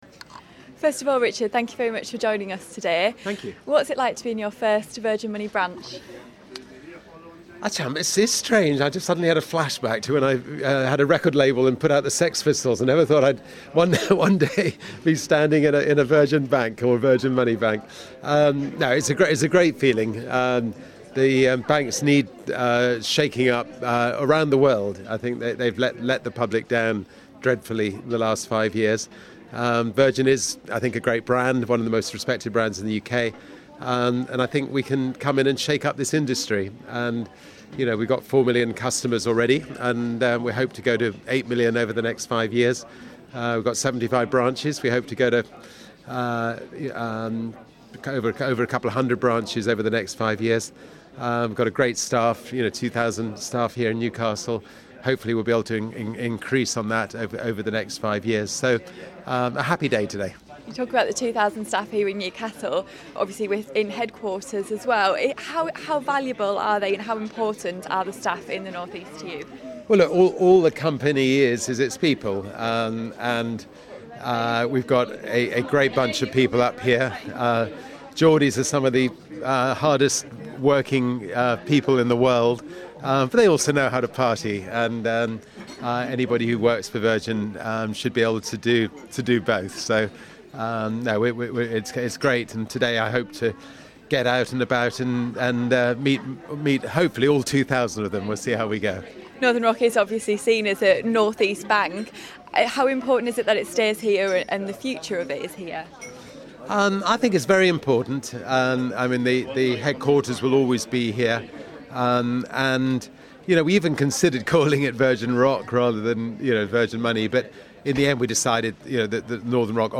Sir Richard Branson speaks to Real Radio as he visits his first branch of Virgin Money in Newcastle